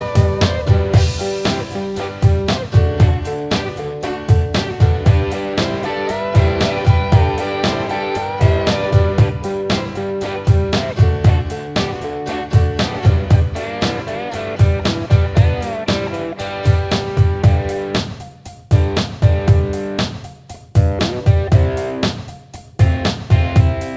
Minus Bass Guitar Rock 3:03 Buy £1.50